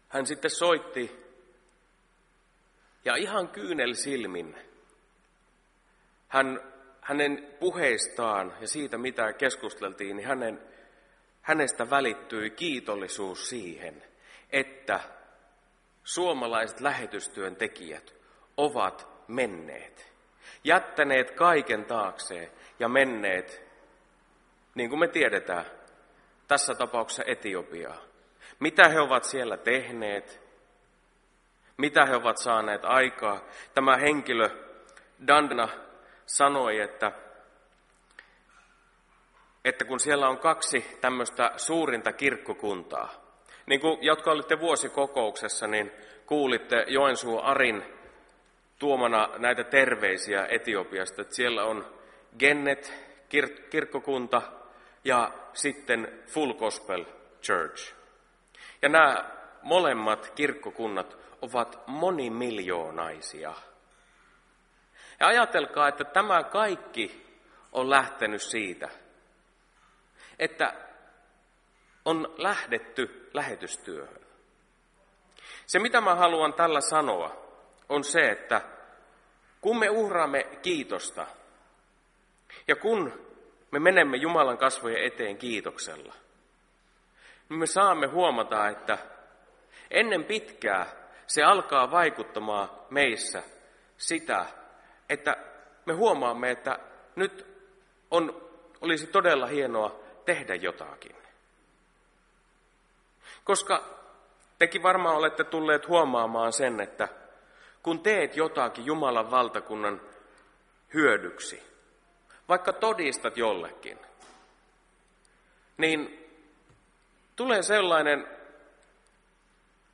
Valitettavasti tilaisuuden alkupuolella oli sähkökatko josta johtuen menetimme puheäänitteen alkuosan.